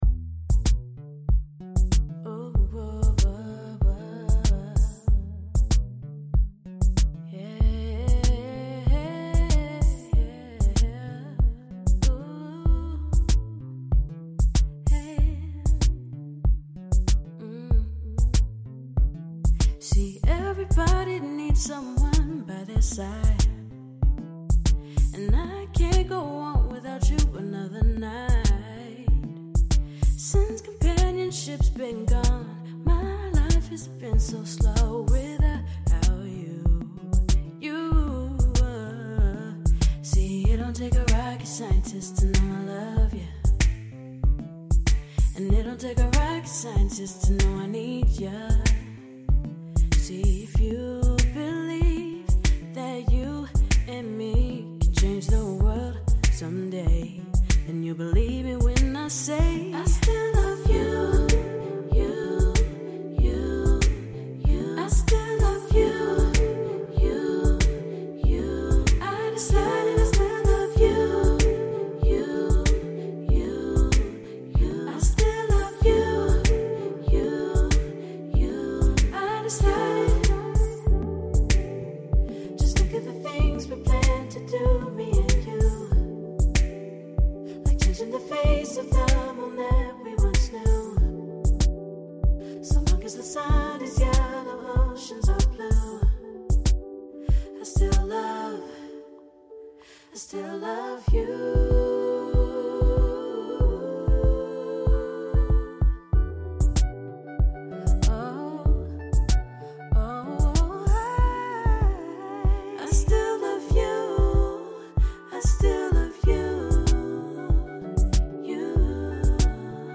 A remake/mash-up